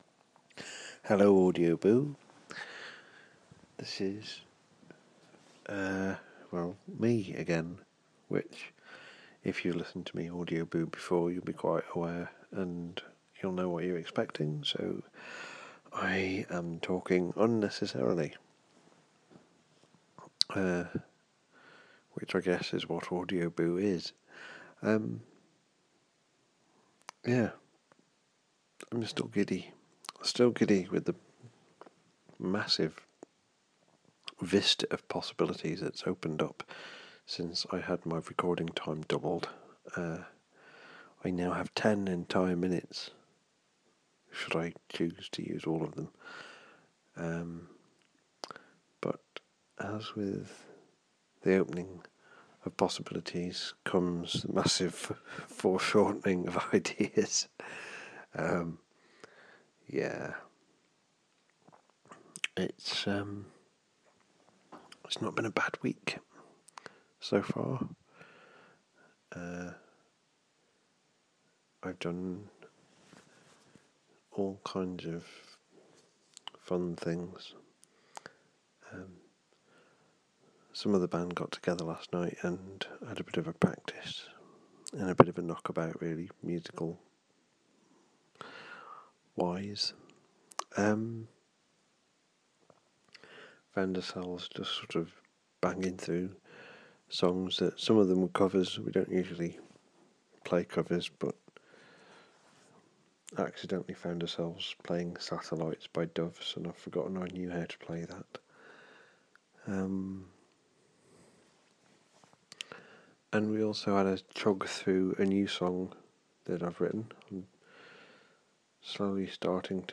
More chat and blather.